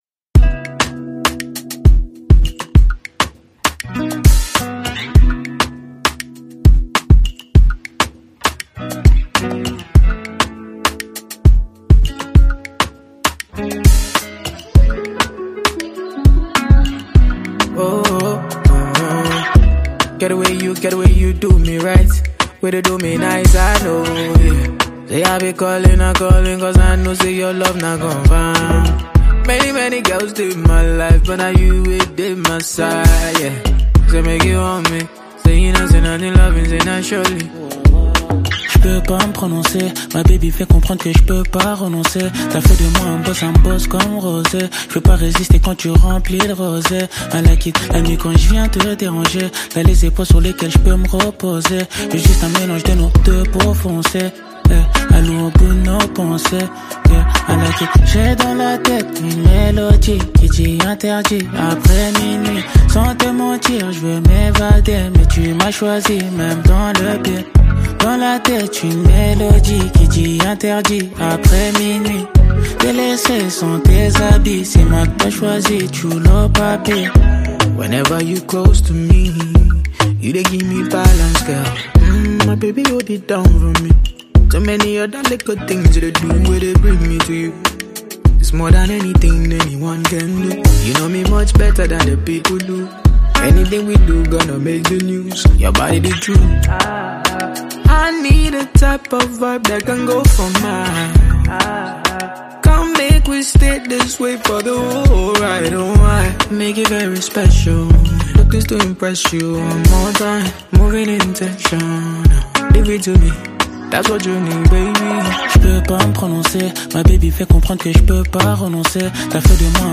a renowned French rapper, singer, and songwriter
With its fresh, seductive sound